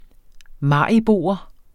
mariboer substantiv, fælleskøn Bøjning -en, -e, -ne Udtale [ ˈmɑˀiˌboˀʌ ] Betydninger person fra Maribo Synonym maribonit Rapportér et problem fra Den Danske Ordbog Den Danske Ordbog .